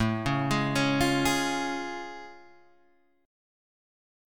A Augmented Major 7th